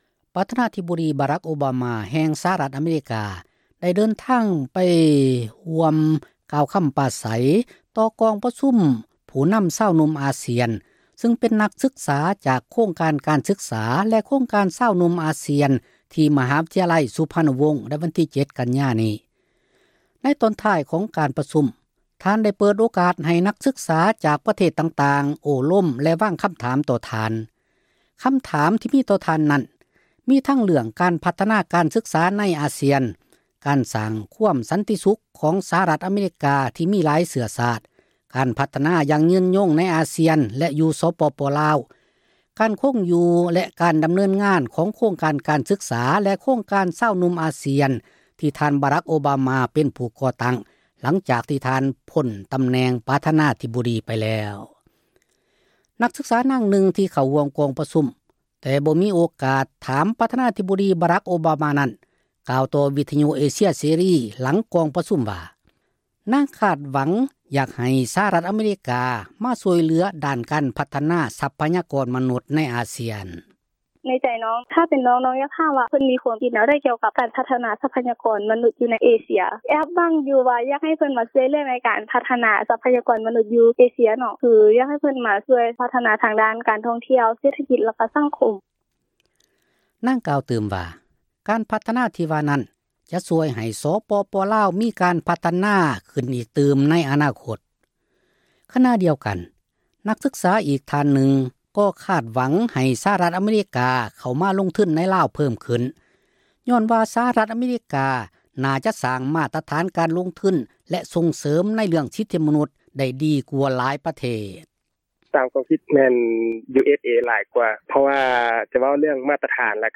ນັກສຶກສາ ນາງນຶ່ງ ທີ່ເຂົ້າຮ່ວມ ກອງປະຊຸມ ແຕ່ບໍ່ມີ ໂອກາດ ໄດ້ຖາມ ທ່ານ ບາຣັກ ໂອບາມາ ນັ້ນ ກ່າວຕໍ່ ວິທຍຸ ເອເຊັຍ ເສຣີ ຫລັງ ກອງປະຊຸມ ວ່າ, ນາງຄາດຫວັງ ຢາກໃຫ້ ສະຫະຣັຖ ອະເມຣິກາ ເຂົ້າມາຊ່ວຍເຫລືອ ດ້ານການພັທນາ ຊັພຍາກອນ ມະນຸດ ໃນອາຊຽນ: